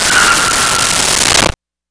Speed Bike Screech Brake 01
Speed Bike Screech Brake 01.wav